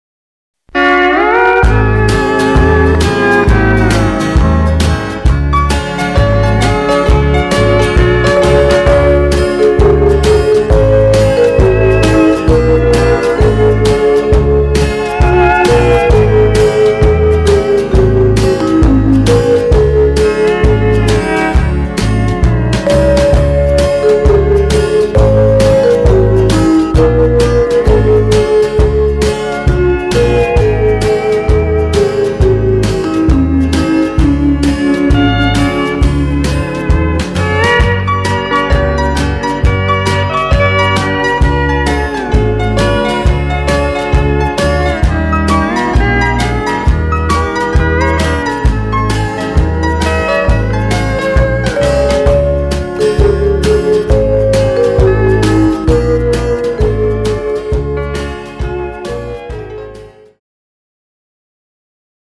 Two Step